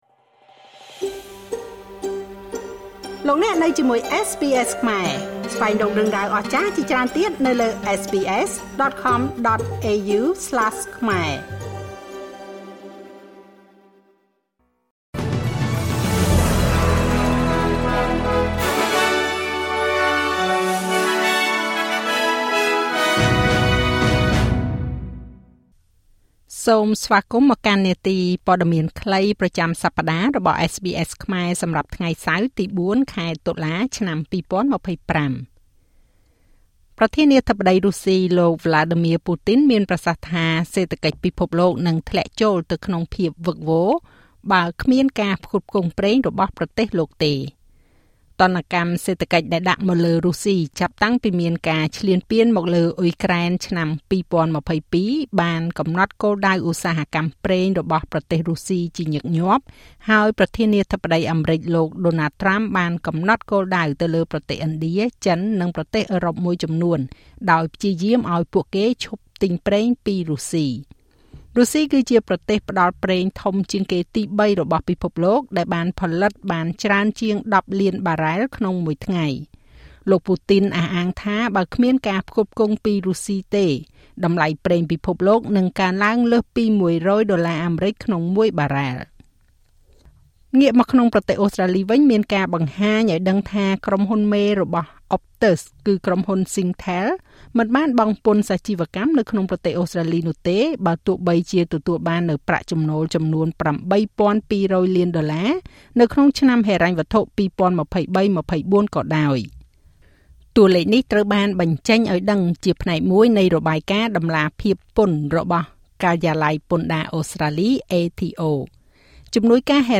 នាទីព័ត៌មានខ្លីប្រចាំសប្តាហ៍របស់SBSខ្មែរ សម្រាប់ថ្ងៃសៅរ៍ ទី៤ ខែតុលា ឆ្នាំ២០២៥